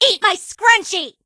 emz_kill_vo_06.ogg